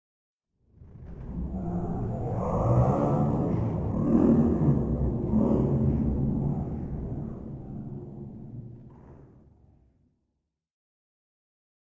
Alien Talking 2 - Big Reverb Long